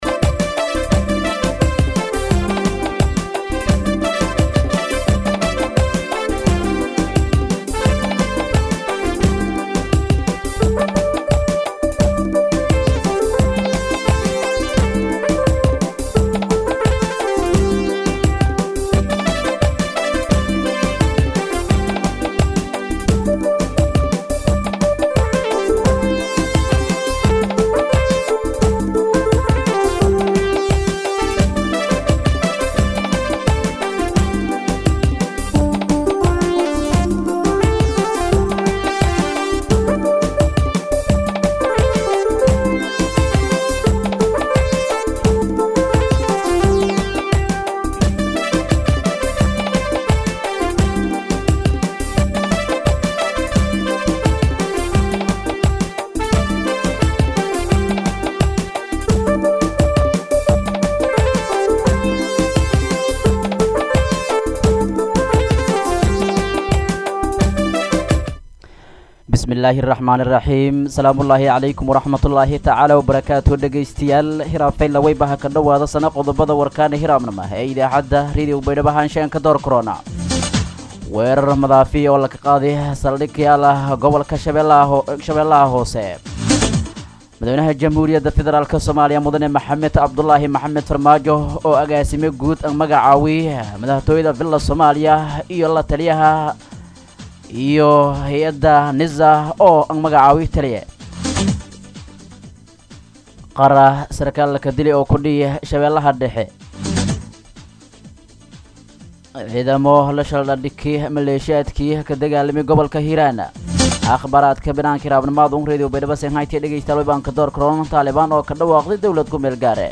DHAGEYSO:-Warka Subaxnimo Radio Baidoa 8-9-2021